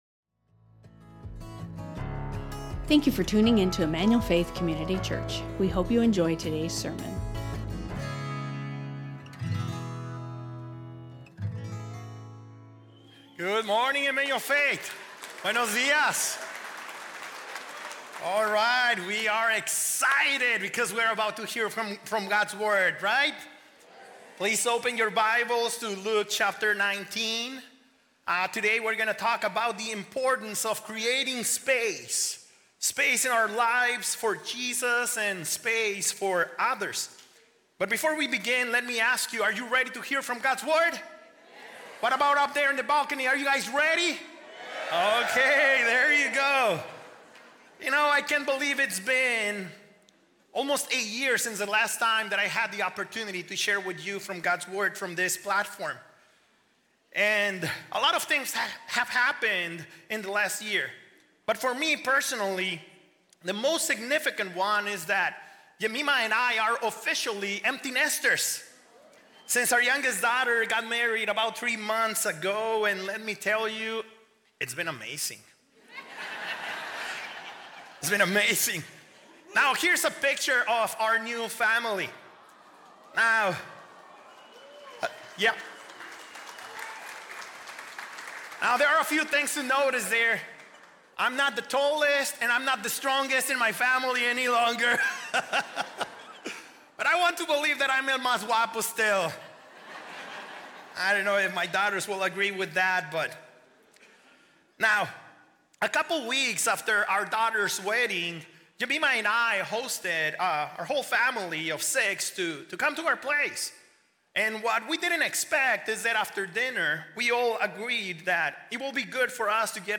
Emmanuel Faith Sermon Podcast Creating Space | Luke 19:1-10 Jul 28 2025 | 00:42:58 1x 00:00 / 00:42:58 Subscribe Share Spotify Amazon Music RSS Feed Share Link Embed